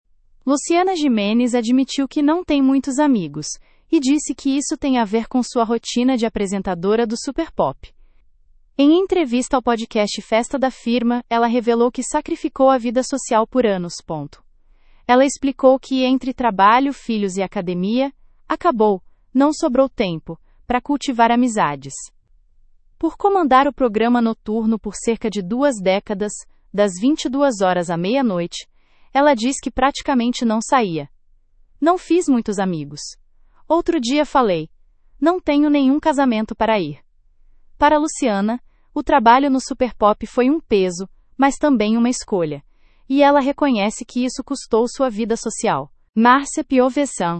Em entrevista ao podcast Festa da Firma, ela revelou que sacrificou a vida social por anos.